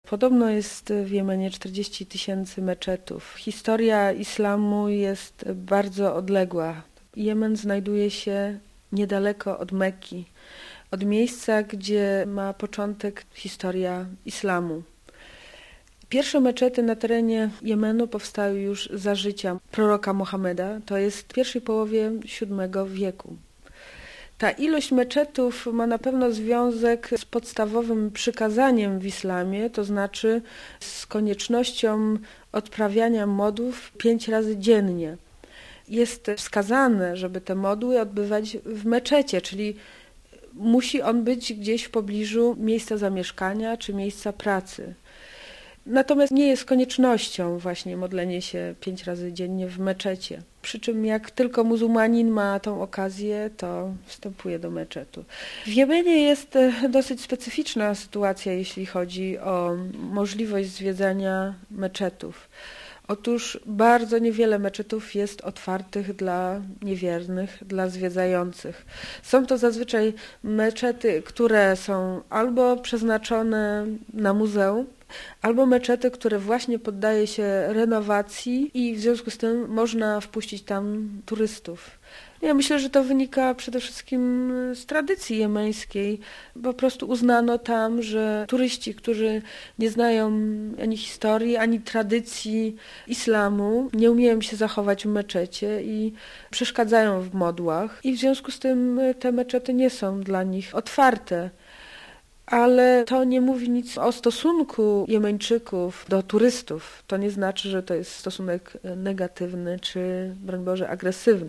Podróżniczka snuje ciekawą opowieść na końcu której posłuchać możemy przez ponad 20 minut muzyki etnicznej z Jemenu.